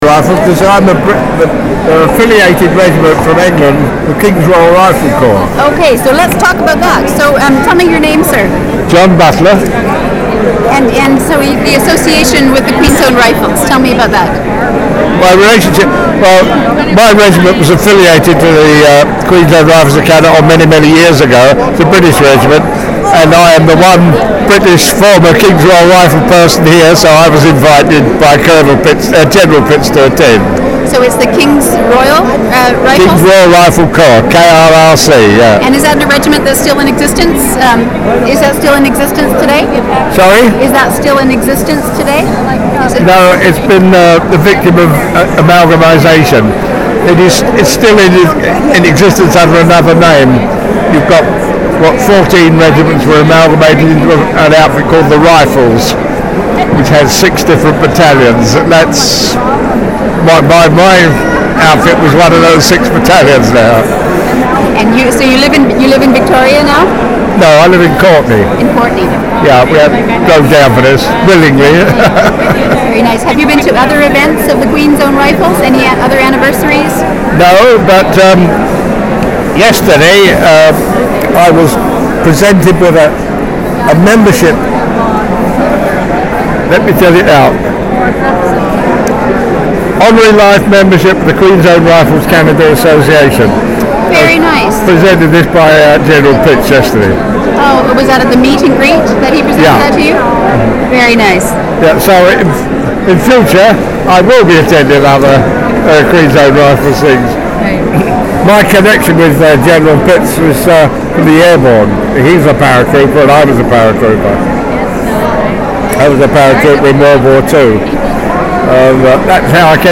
• Interview took place during the Queen's Own Rifles of Canada Vancouver Island Branch 150th Anniversary Celebration.
• Canadian Military Oral History Collection